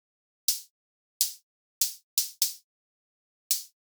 hihat-open.ogg